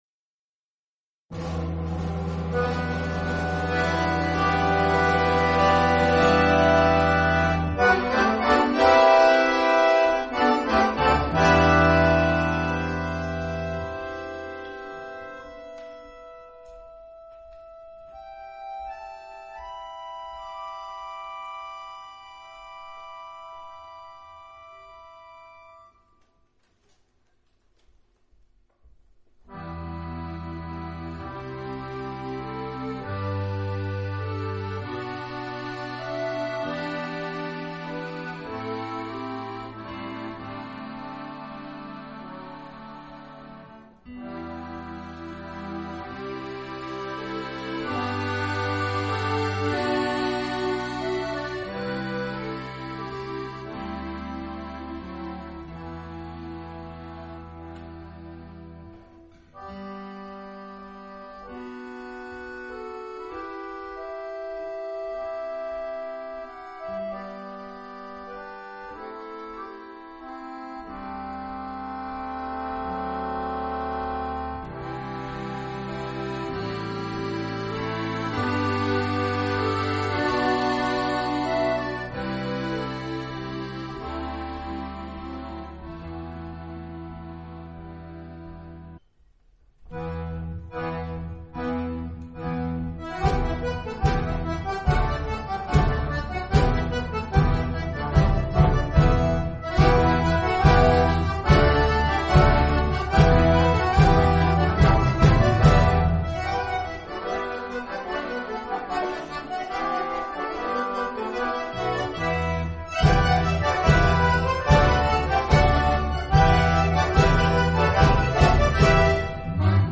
2004 – Akkordeonorchester Neustadt bei Coburg e. V.
Irische Suite (Matyas Seiber; bearb. Rudolf Würthner;  Prelude – Reel – Air – Jig)